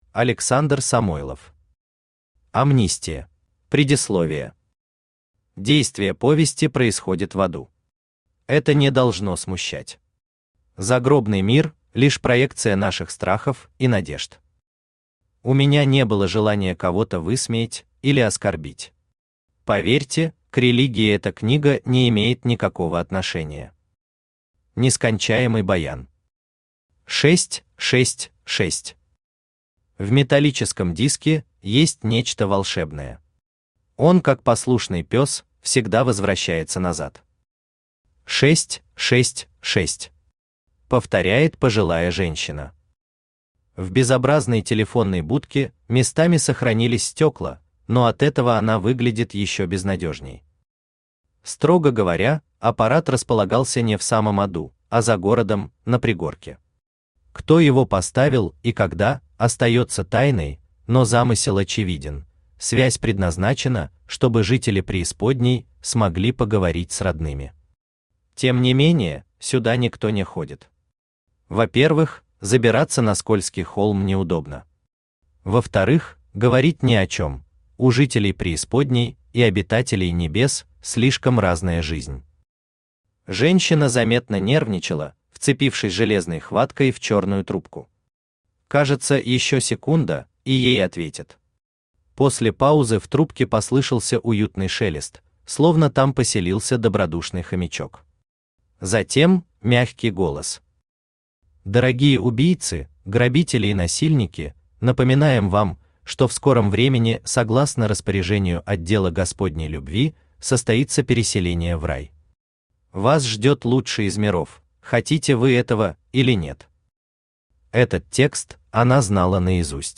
Аудиокнига Амнистия | Библиотека аудиокниг
Aудиокнига Амнистия Автор Александр Сергеевич Самойлов Читает аудиокнигу Авточтец ЛитРес.